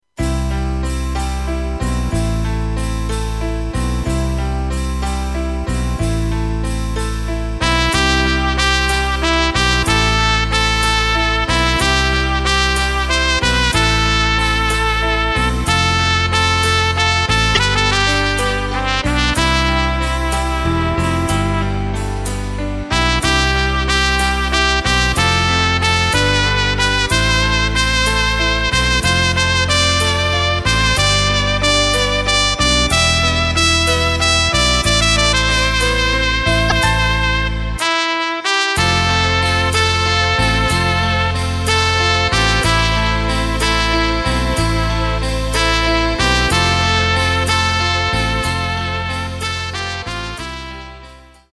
Takt:          4/4
Tempo:         93.00
Tonart:            C
Trompeten Solo!